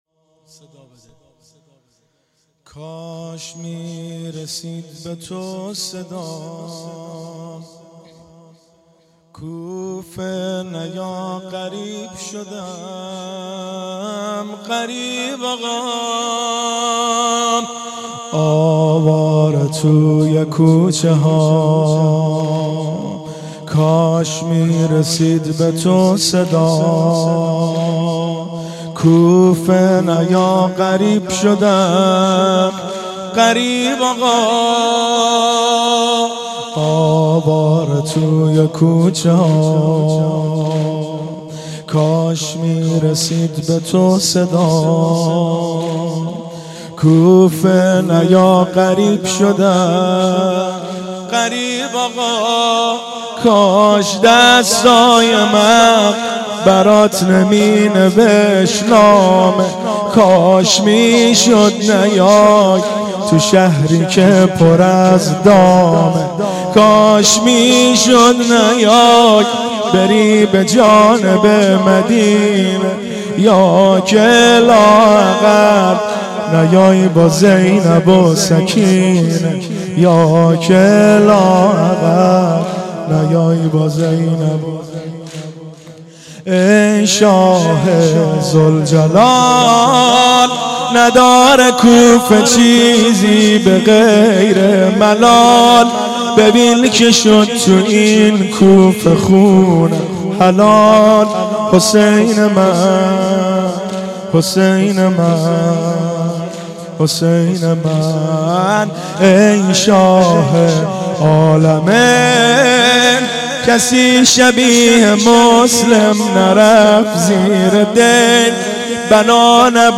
صوت مراسم شب اول محرم ۱۴۳۷ هیئت غریب مدینه امیرکلا ذیلاً می‌آید: